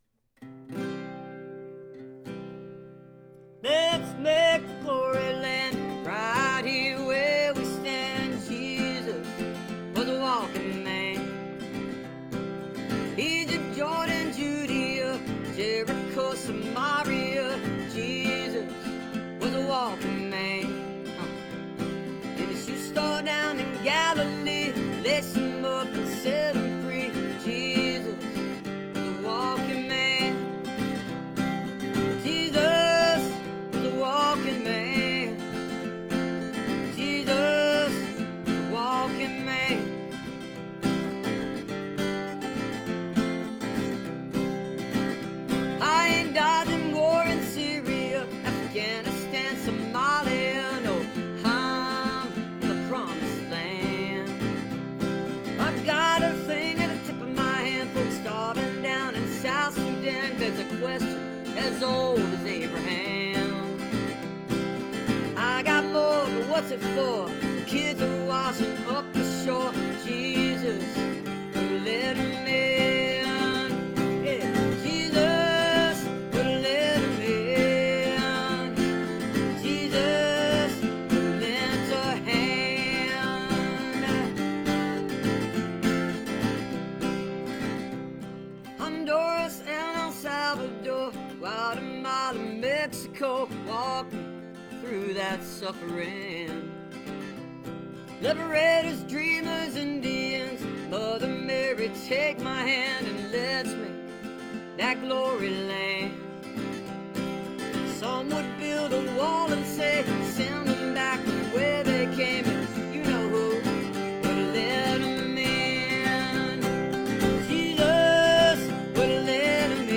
(captured from the facebook live video stream)